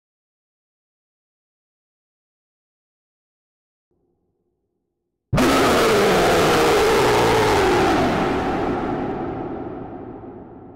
Siren Head Jumpscare (Loud)
sirenheadloud.m4a